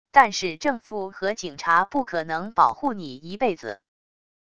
但是政fǔ和警察不可能保护你一辈子wav音频生成系统WAV Audio Player